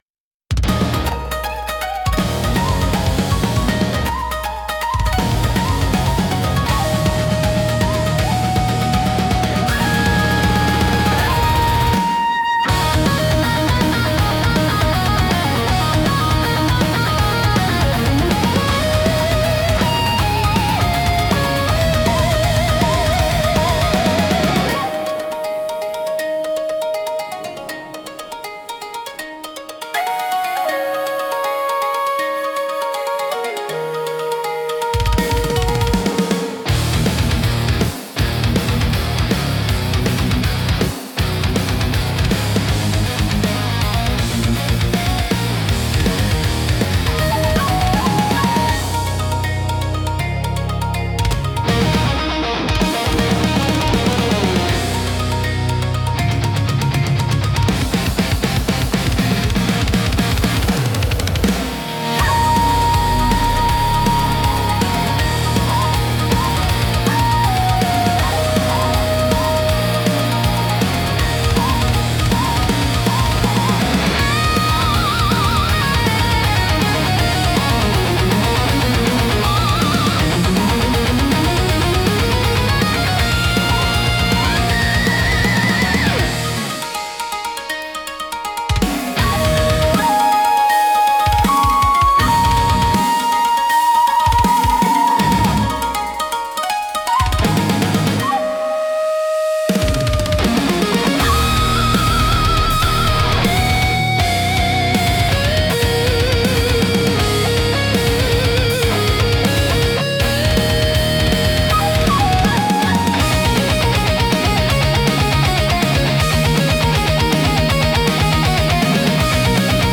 尺八の幽玄な響きと琴の繊細な調べが、重厚なギタートーンや高速ビートと絡み合い、独自の緊張感とダイナミズムを生み出します。
聴く人に力強さと神秘性を同時に感じさせ、日本古来の精神と現代のエネルギーを融合したインパクトを与えます。